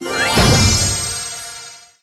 From The Cutting Room Floor Jump to navigation Jump to search File File history File usage Metadata Clash_Royale_huge_magical_chest_tap_01.ogg  (Ogg Vorbis sound file, length 2.0 s, 59 kbps) This file is an audio rip from a(n) Android game.
Clash_Royale_huge_magical_chest_tap_01.ogg